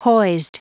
Prononciation du mot hoised en anglais (fichier audio)
Prononciation du mot : hoised